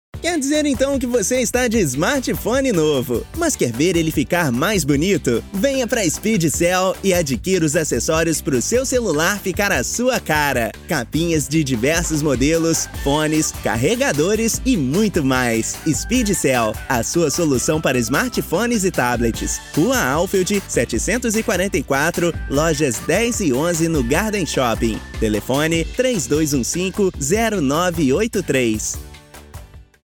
PT BR DD COM 01 Commercials Male Portuguese(Brazilian)